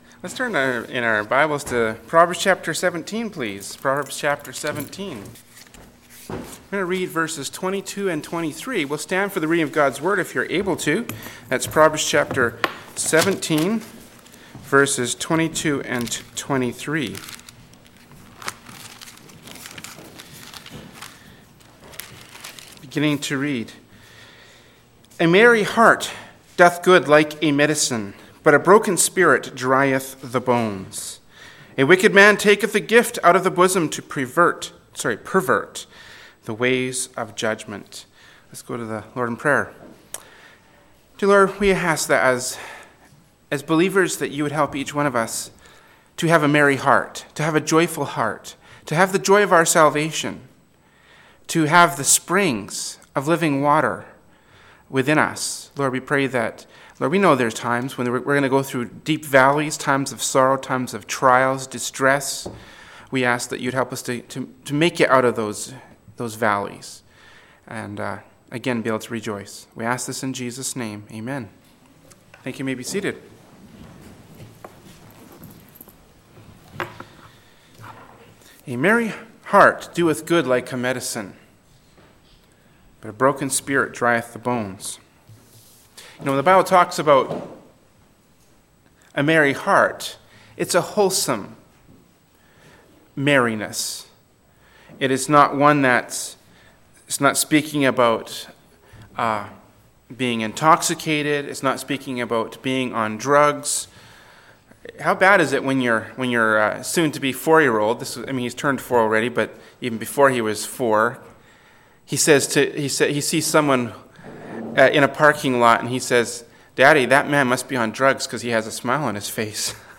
“Proverbs 17:22-23” from Sunday School Service by Berean Baptist Church.